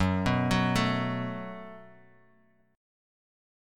F#mbb5 chord